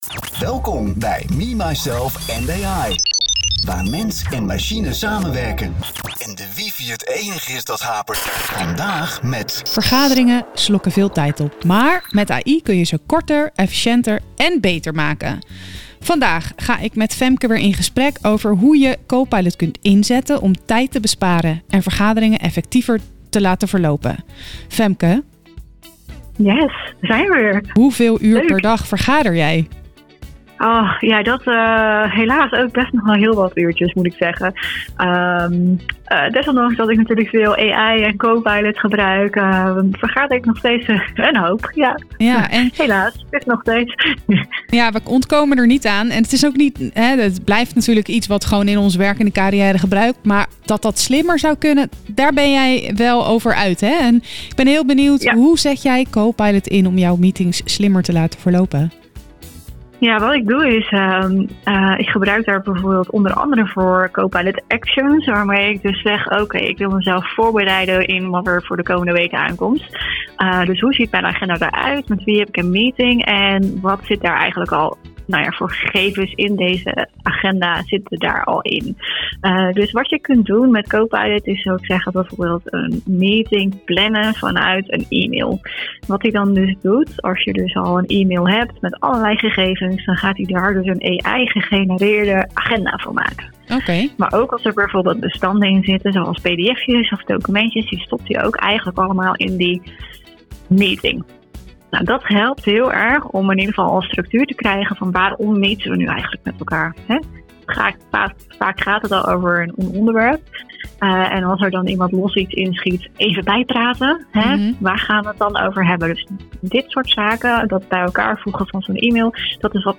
Onze gast vertelt hoe je Copilot inzet om tijd te besparen en vergaderingen effectiever te maken.